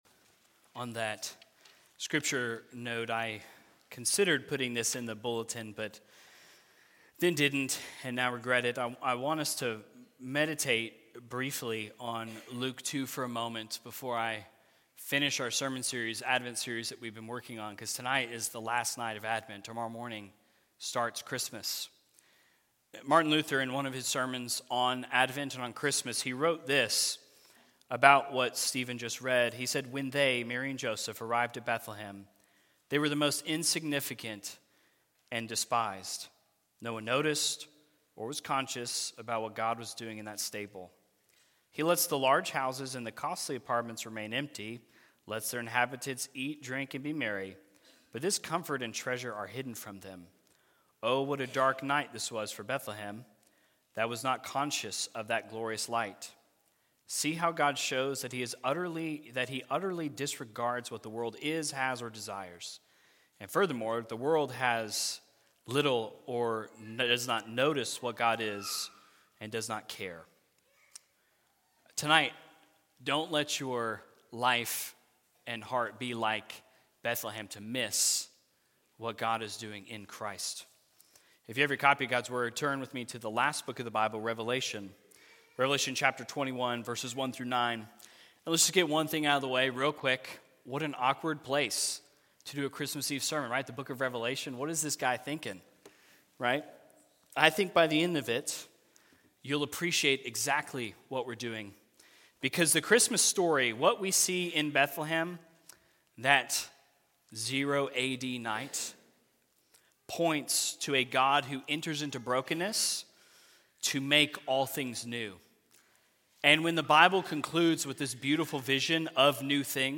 Because this was a Christmas Eve special service, there is no video available.